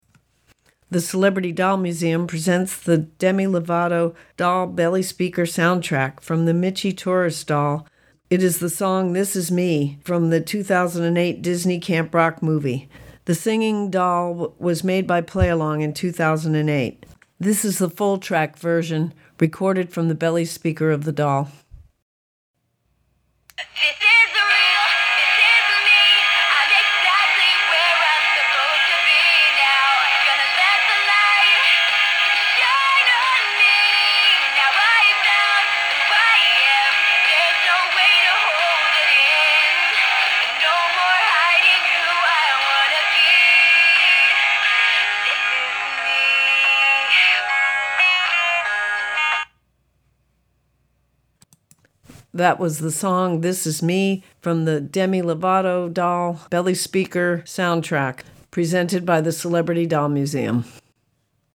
are from the belly speaker of the doll
full track doll version